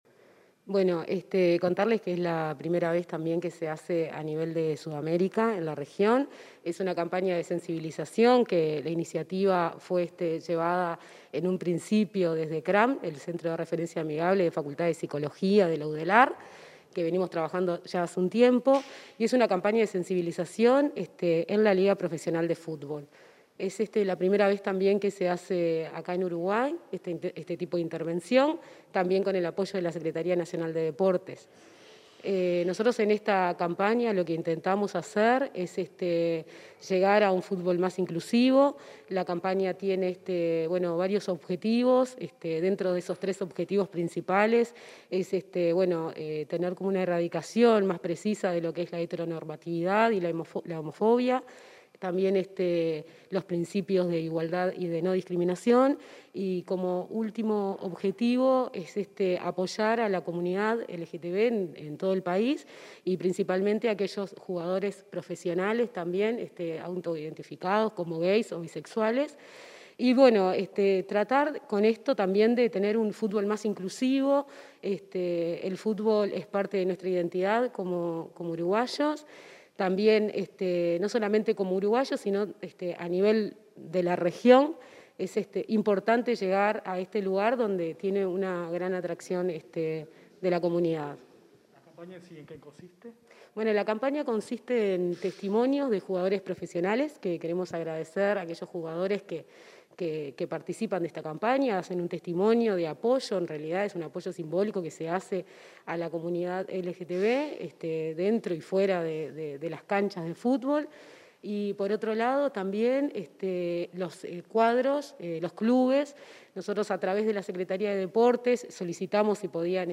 Entrevista a la directora del Mides Rosa Méndez